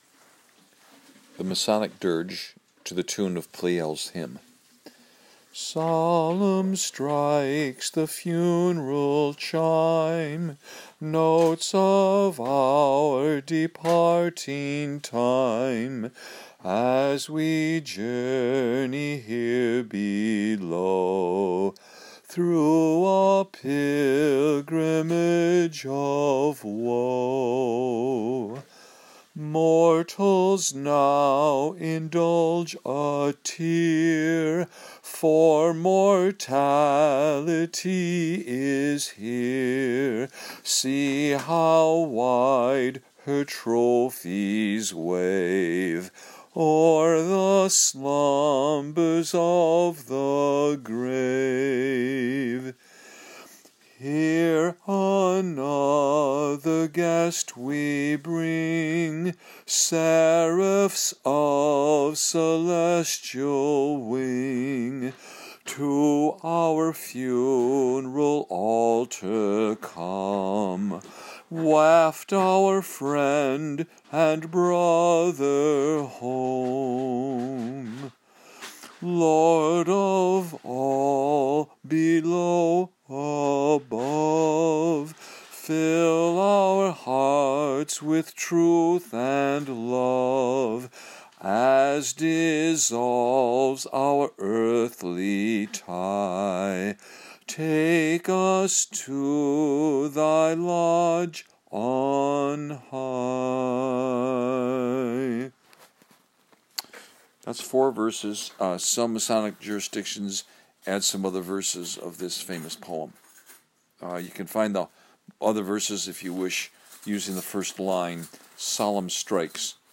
Click to hear or download the Masonic Dirge sung acappella with added explanation  mp3
(Funeral Dirge for MM Degree)